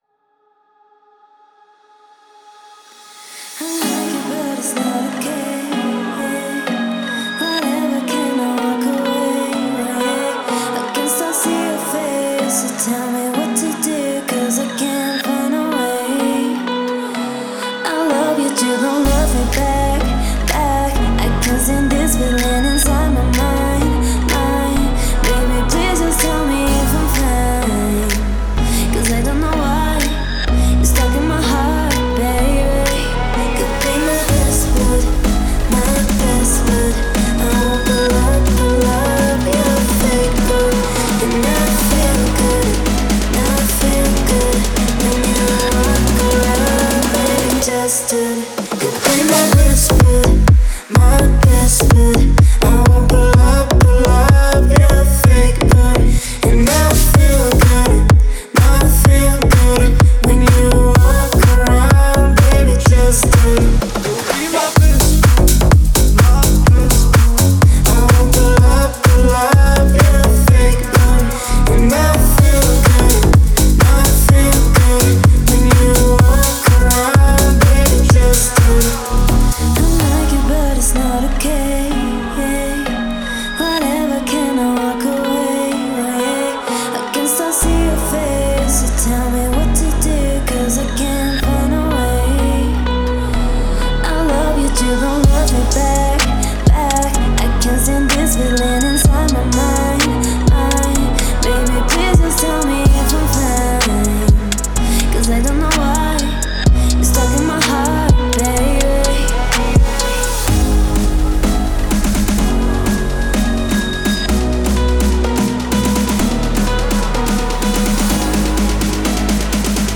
яркая и зажигательная песня